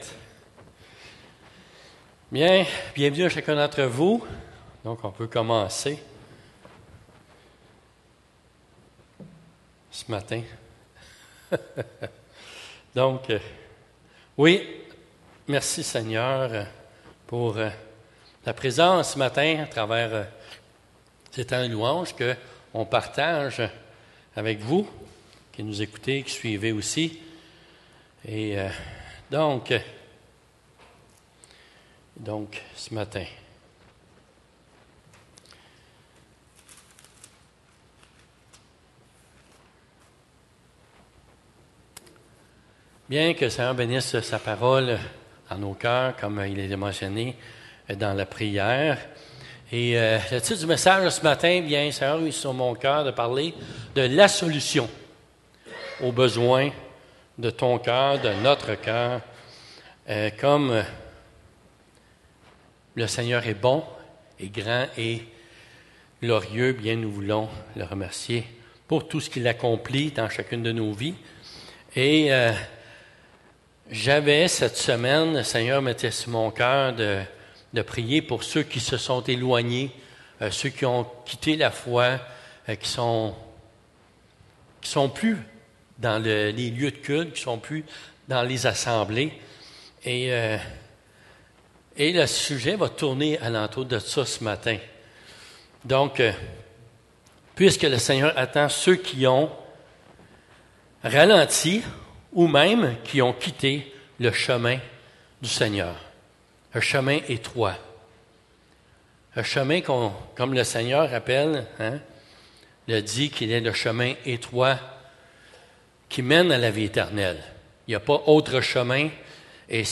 Prédication précédent